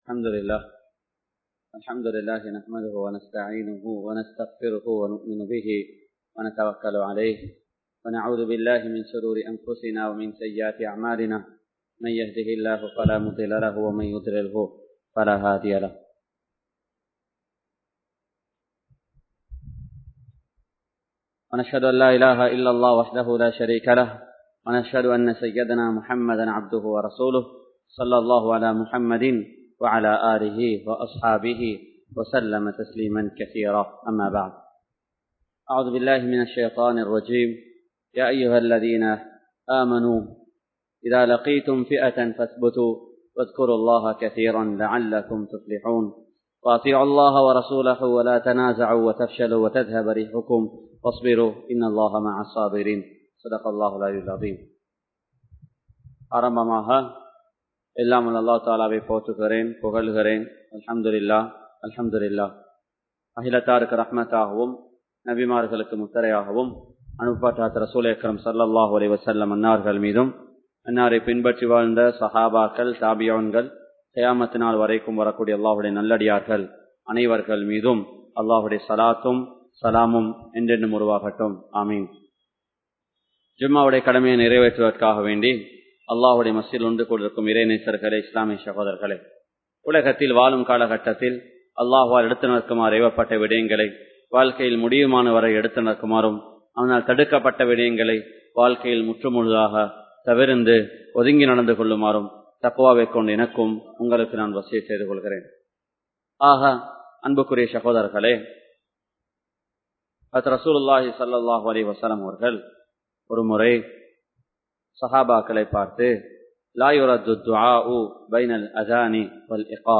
Islam Koorum Poathanai (இஸ்லாம் கூறும் போதனை) | Audio Bayans | All Ceylon Muslim Youth Community | Addalaichenai
Ar Rahmath Jumua Masjidh